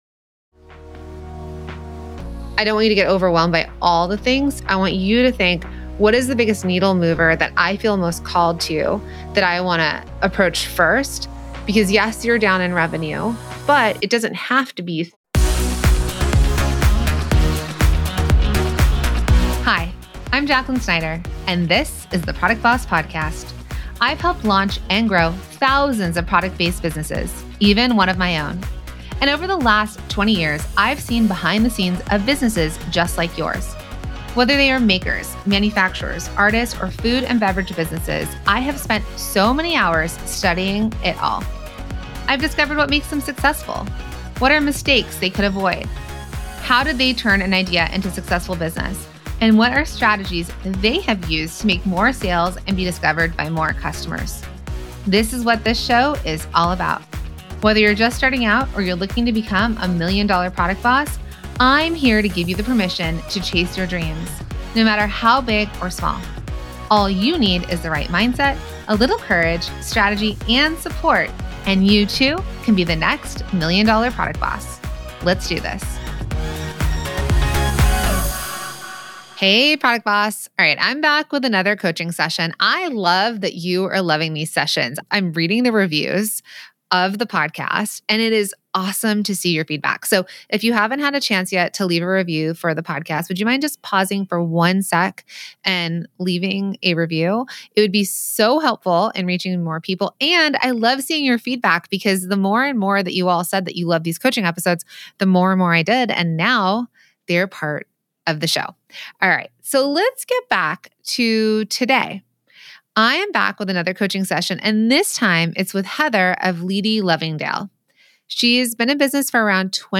| Coaching Session